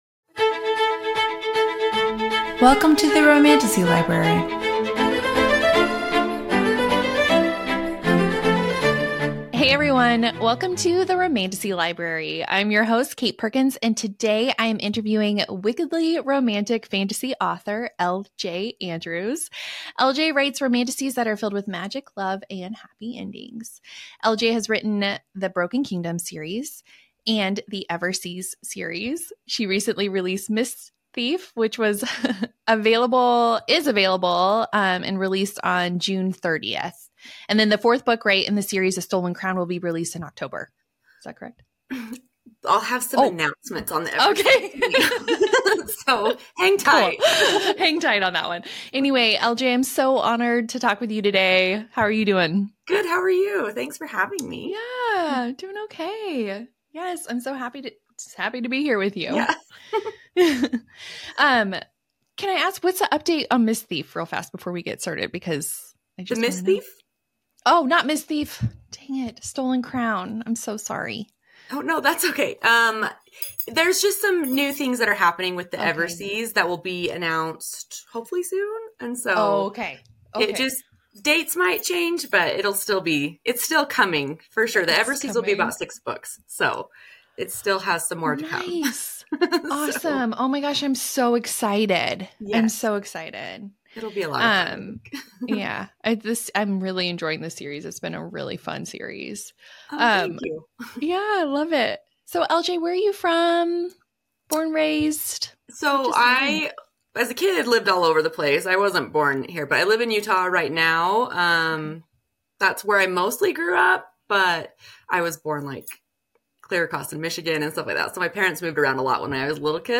I hope you enjoy this conversation!